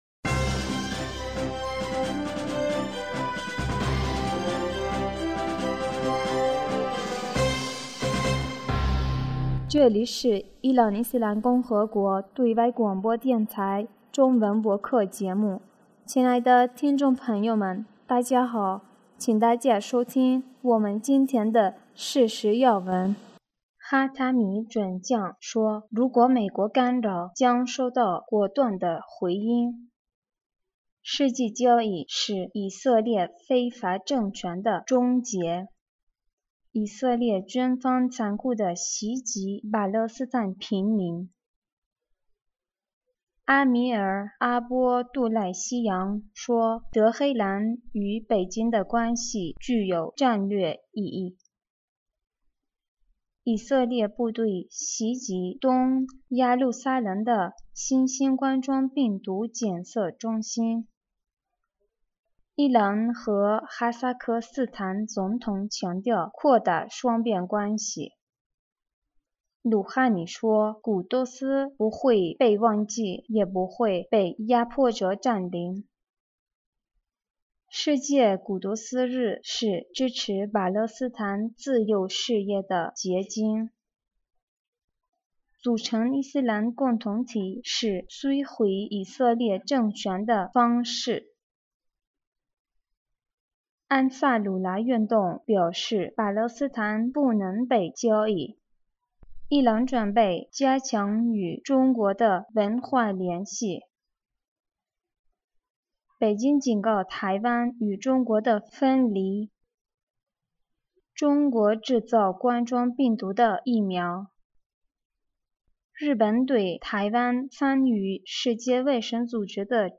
2020年5月20日 新闻